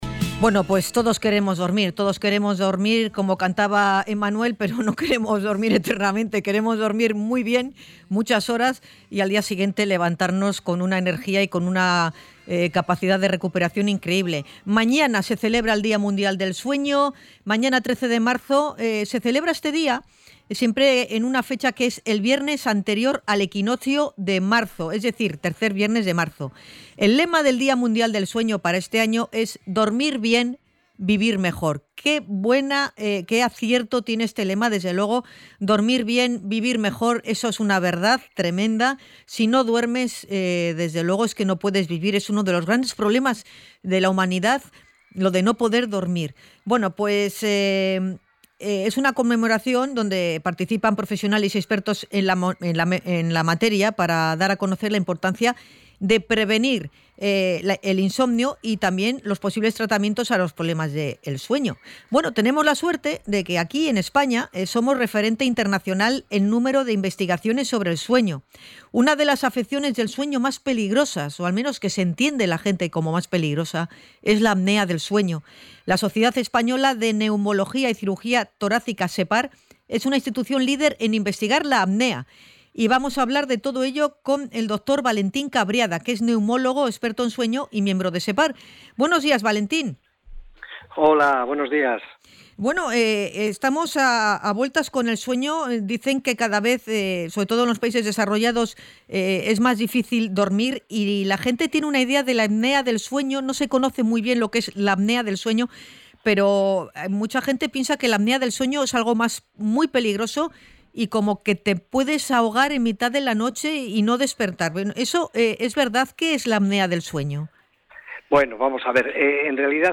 ENTREV.-APNEA.mp3